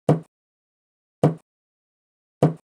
hit_table_se.ogg